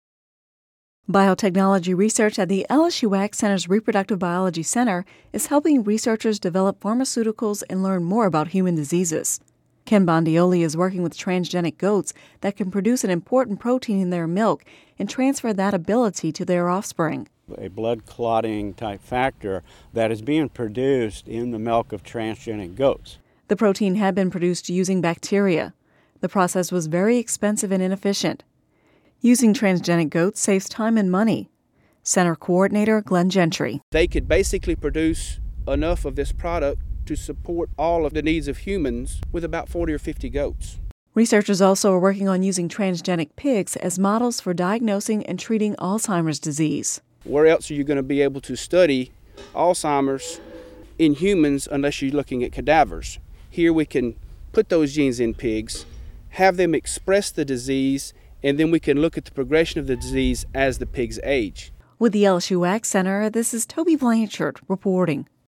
(Radio News 03/14/11) Biotechnology research at the LSU AgCenter’s Reproductive Biology Center is helping researchers develop pharmaceuticals and learn more about human diseases.